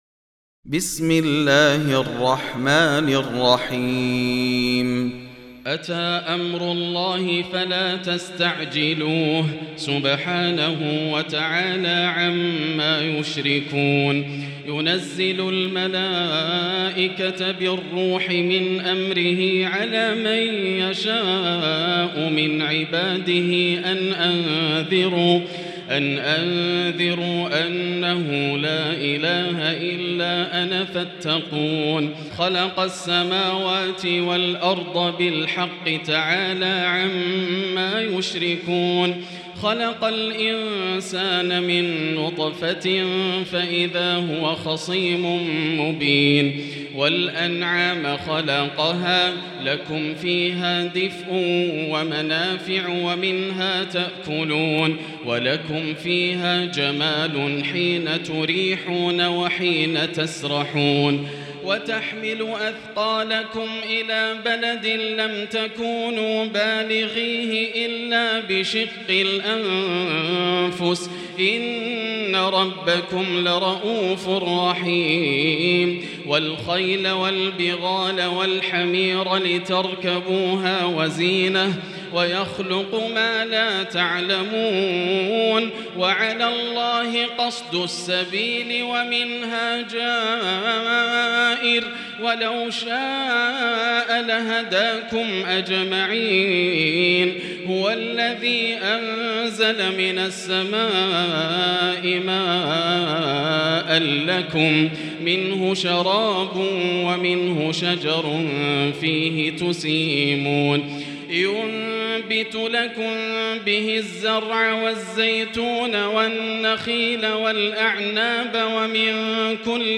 المكان: المسجد الحرام الشيخ: فضيلة الشيخ ياسر الدوسري فضيلة الشيخ ياسر الدوسري النحل